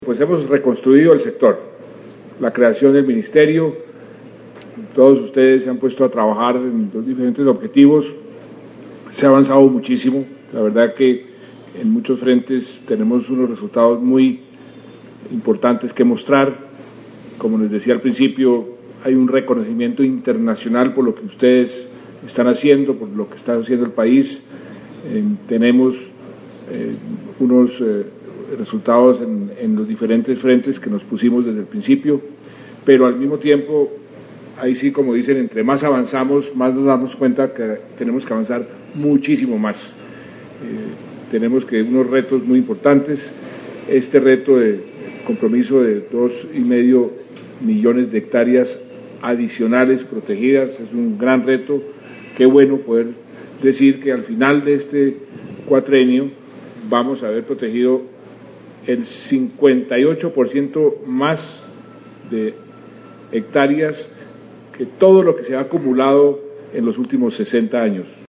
Declaraciones del Presidente de la República, Juan Manuel Santos Calderón audio